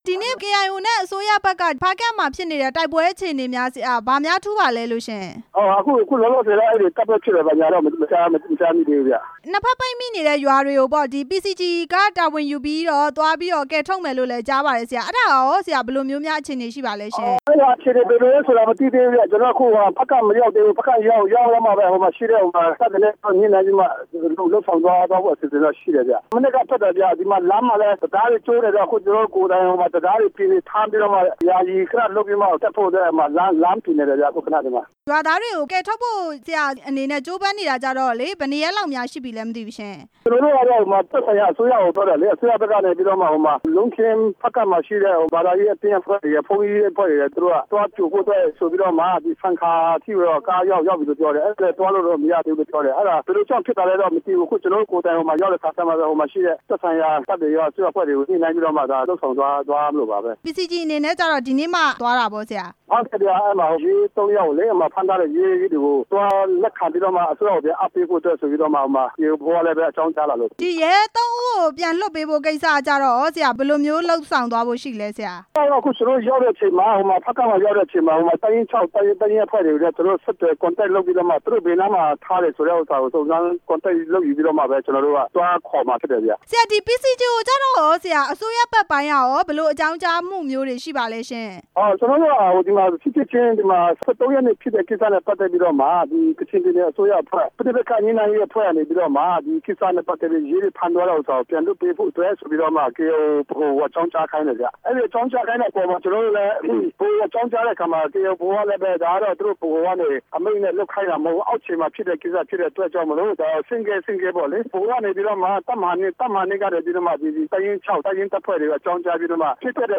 မေးမြန်း ချက်